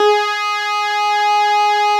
Added synth instrument
snes_synth_056.wav